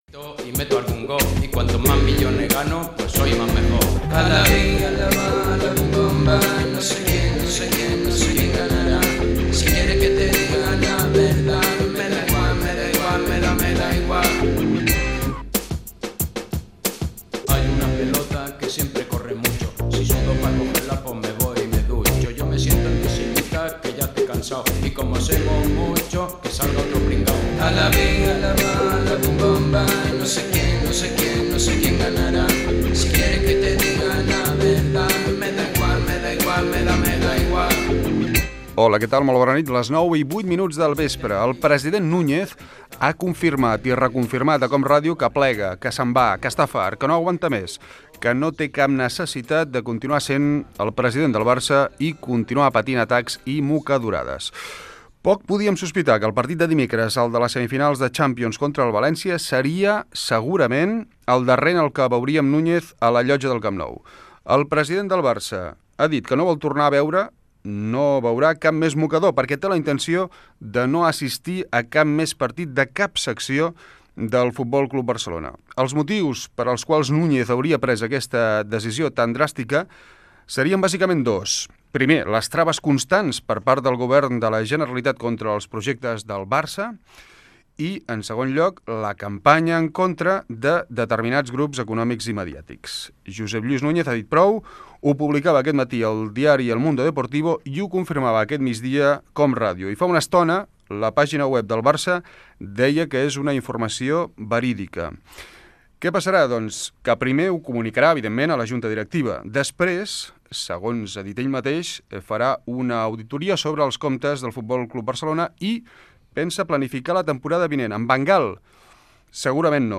Esportiu
FM
Fragment extret de l'arxiu sonor de COM Ràdio